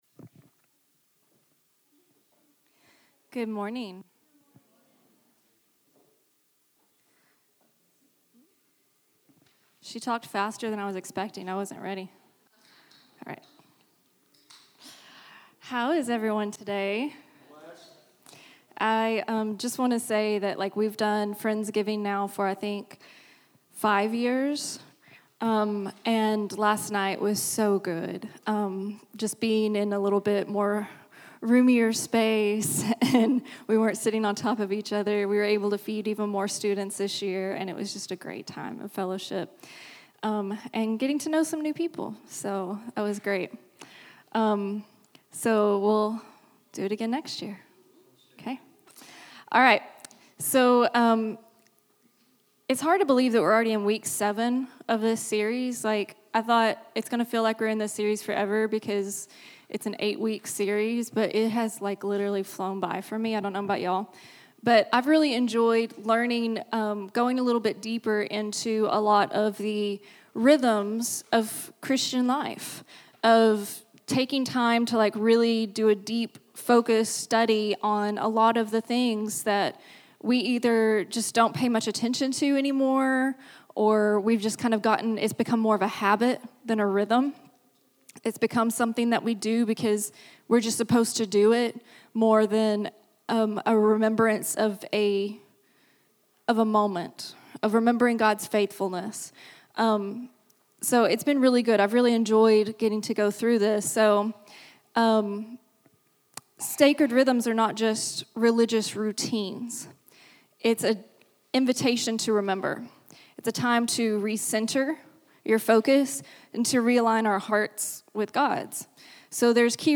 Download Download Sermon Notes Message Notes.pdf Kid Lesson Notes.pdf Anointing isn’t magic oil or an empty church tradition—it’s God marking us with His Spirit.